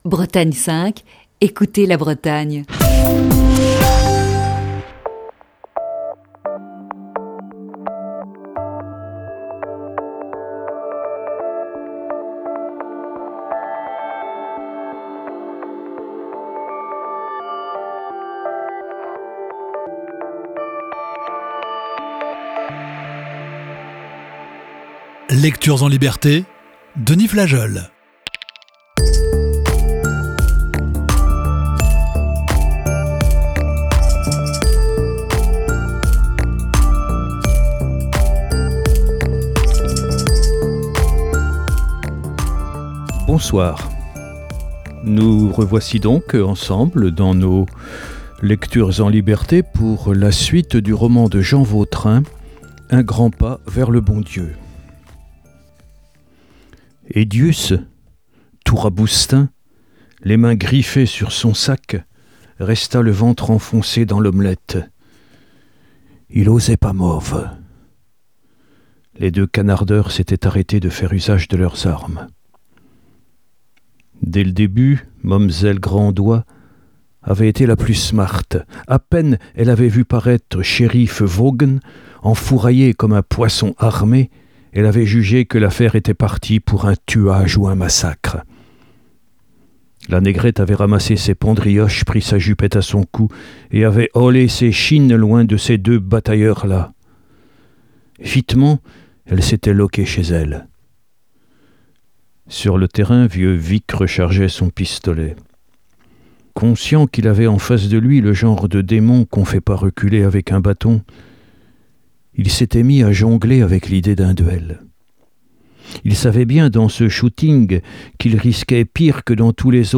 Voici ce soir la sixième partie de ce récit.